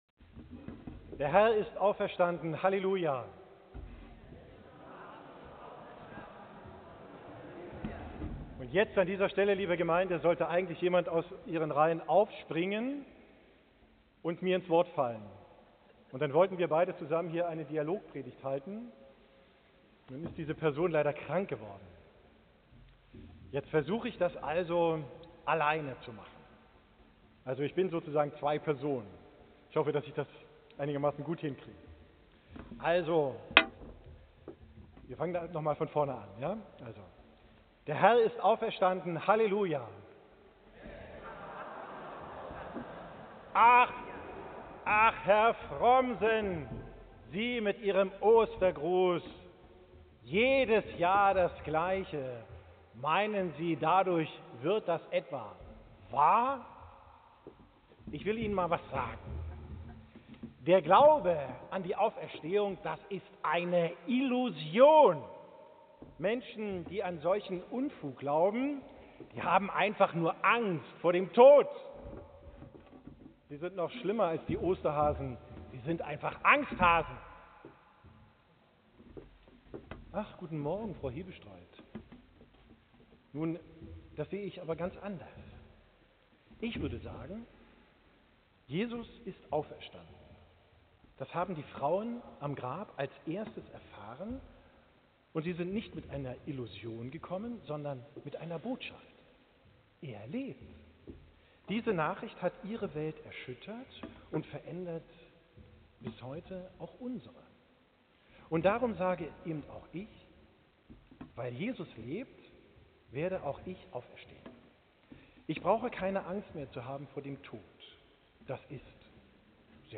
Predigt vom Ostersonntag 10 Uhr, 20.